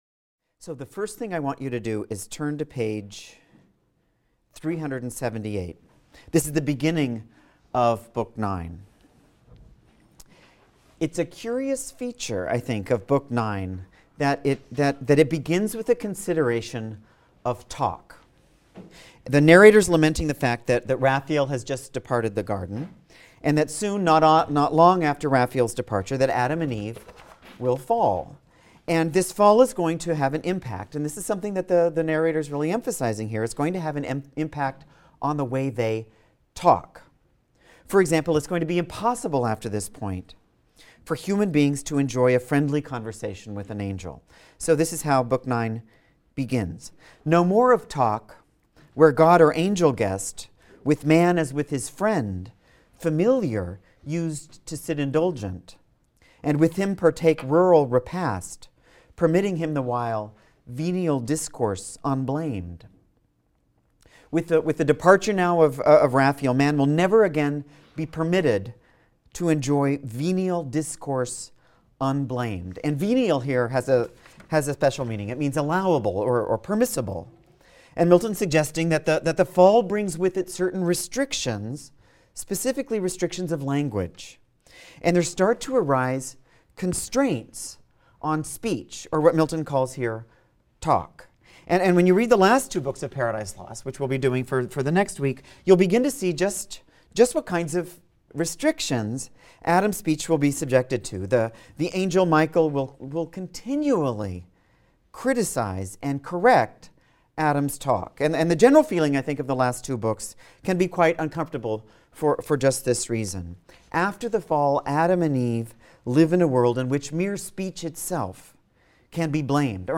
ENGL 220 - Lecture 18 - Paradise Lost, Books IX-X | Open Yale Courses